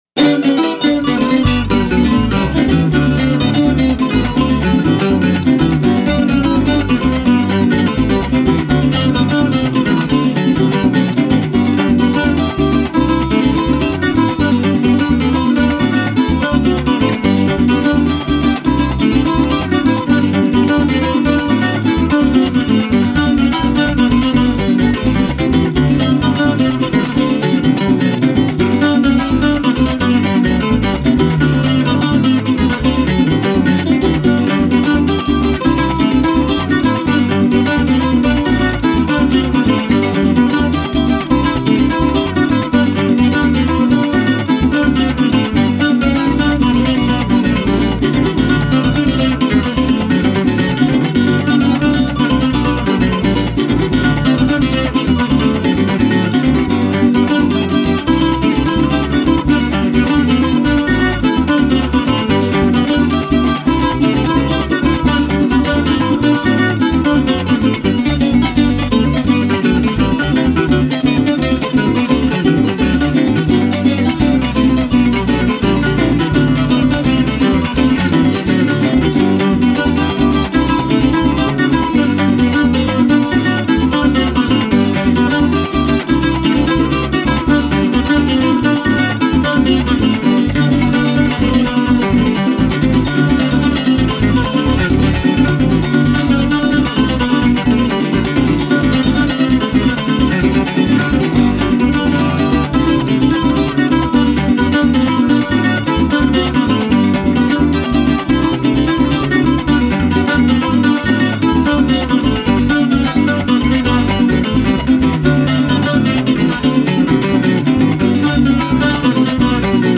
La música de América Latina.